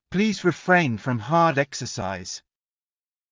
ﾌﾟﾘｰｽﾞ ﾘﾌﾚｲﾝ ﾌﾛﾑ ﾊｰﾄﾞ ｴｸｻｻｲｽﾞ